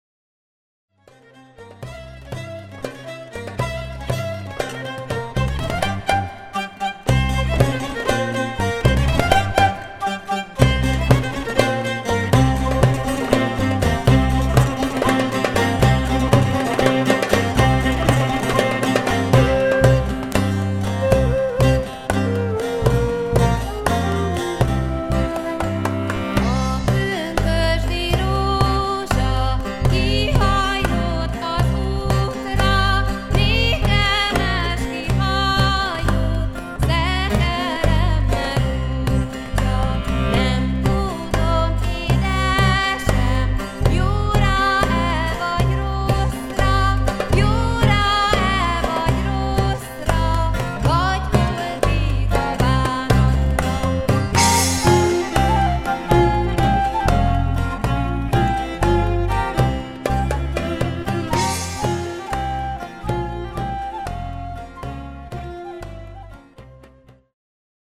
Traditional Moldvian folk song